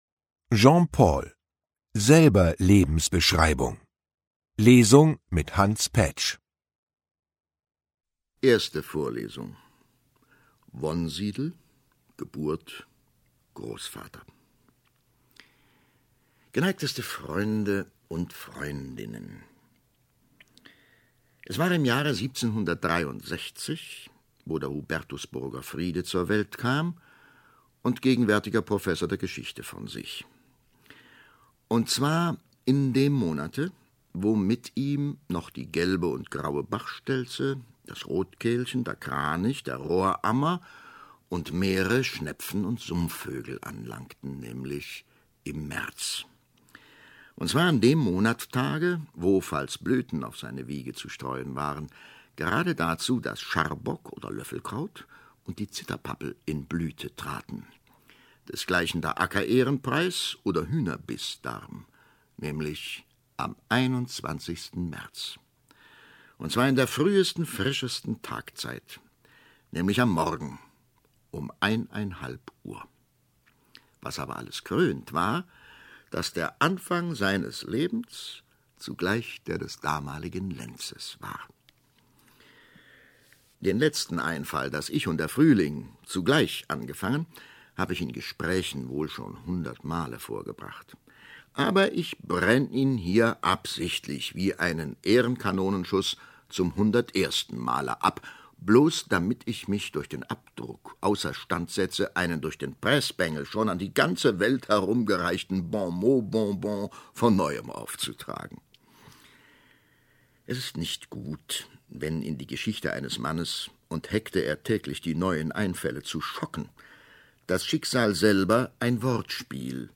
Ungekürzte Lesung mit Hans Paetsch (1 mp3-CD)
Hans Paetsch (Sprecher)